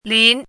chinese-voice - 汉字语音库
lin2.mp3